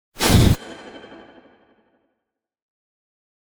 mandrake fvtt13data/Data/modules/psfx/library/ranged-magic/generic/missile/001